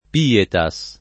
pietas [lat. p & eta S ] s. f.